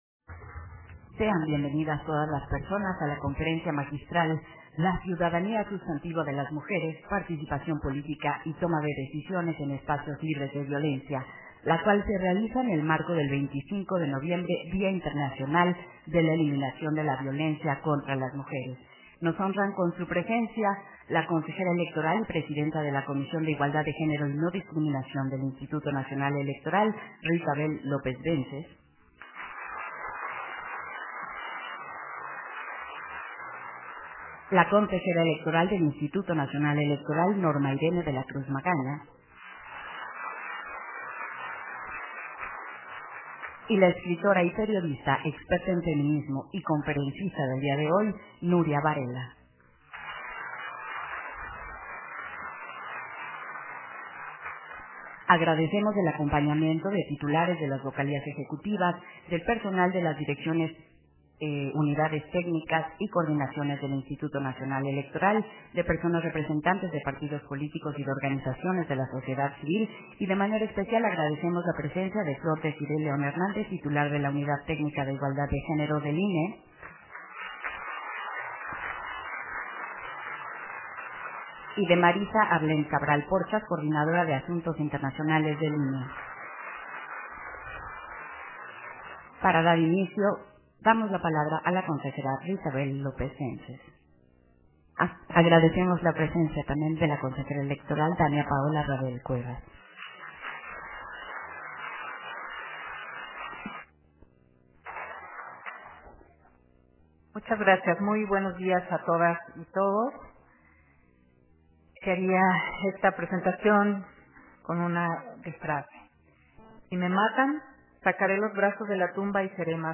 211123_AUDIO_CONFERENCIA-MAGISTRAL